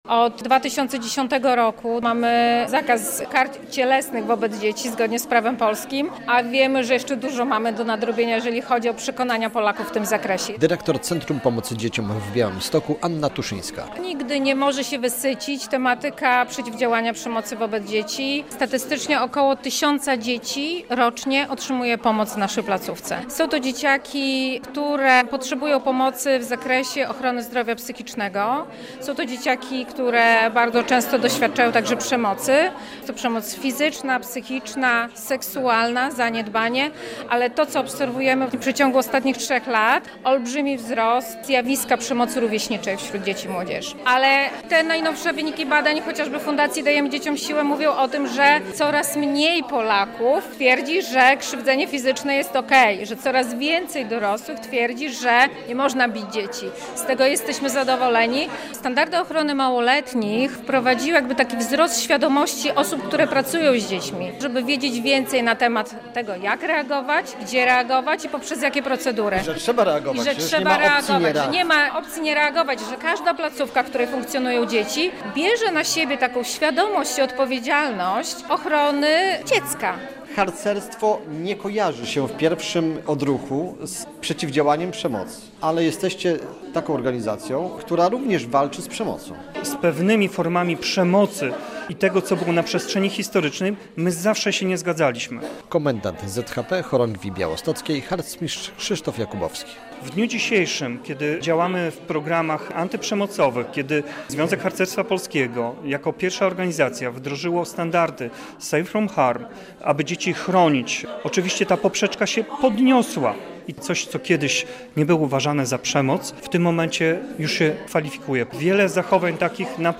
Podsumowanie kampanii "Dzieciństwo bez przemocy" - relacja